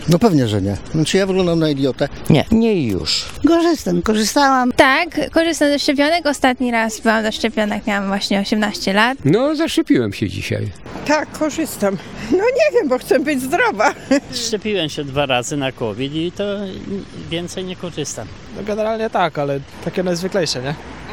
Przy okazji zapytaliśmy mieszkańców Stargardu, czy… się szczepią.